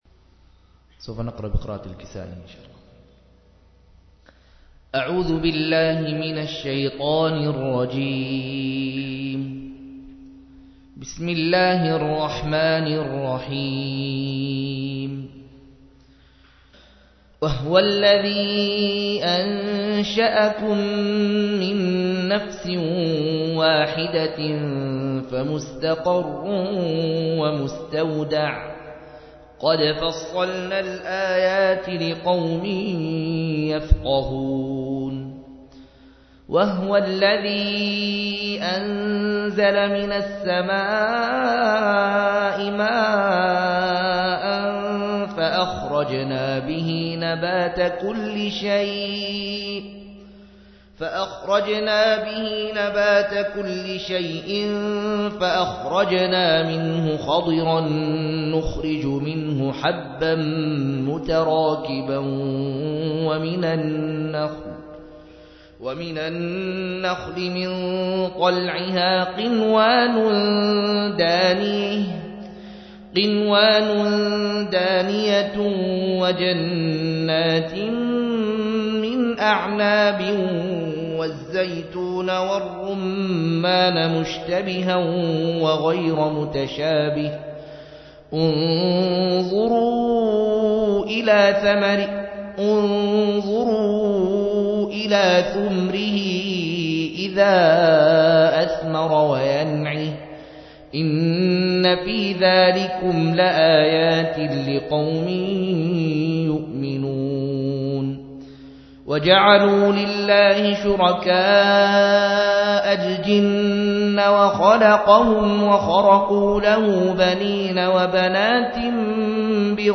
134- عمدة التفسير عن الحافظ ابن كثير رحمه الله للعلامة أحمد شاكر رحمه الله – قراءة وتعليق –